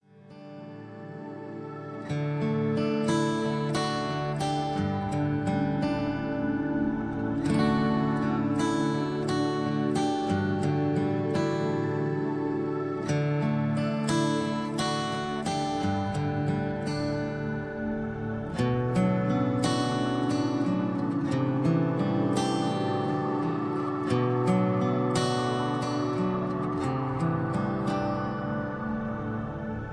Karaoke Mp3 Backing Tracks
karaoke